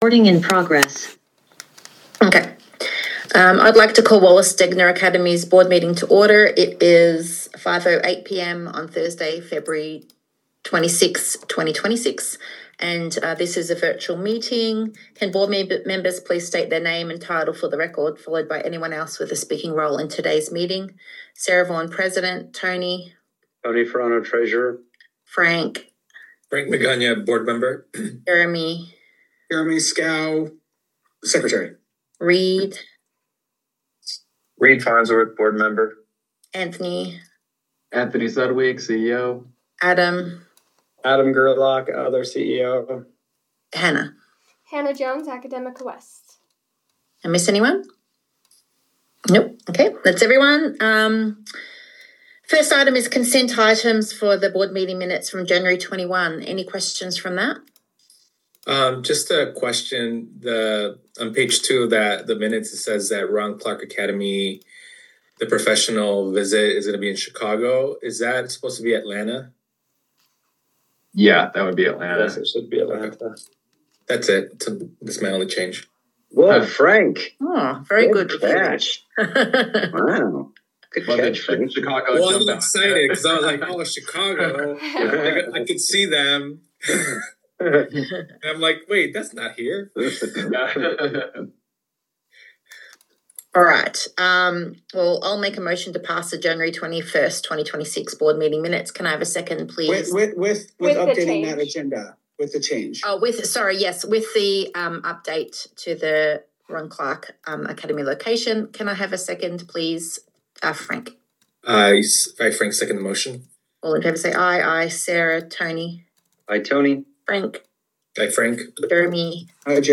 Meeting
One or more board members may participate electronically or telephonically pursuant to UCA 52-4-207.